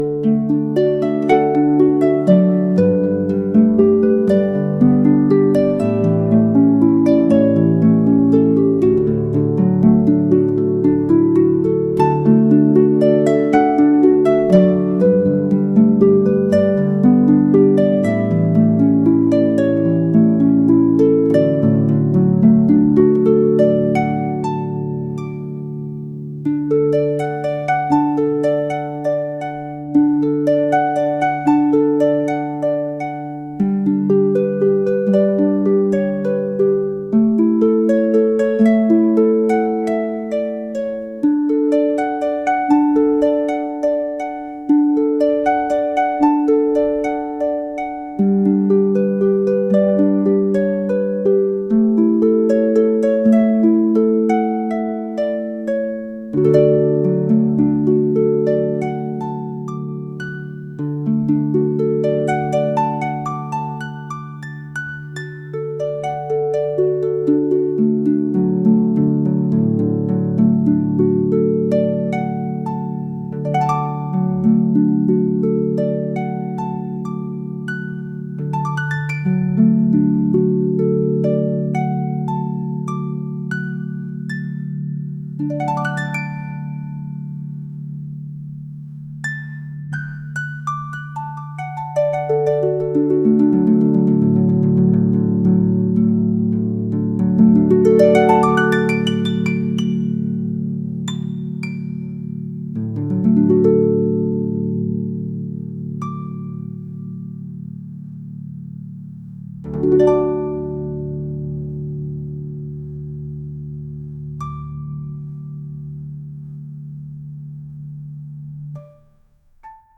Calm Music